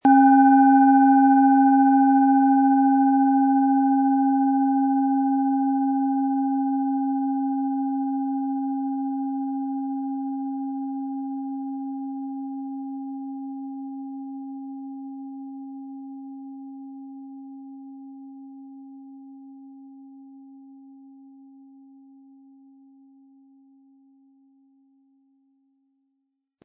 OM Ton
Planetenschale®
SchalenformBihar
MaterialBronze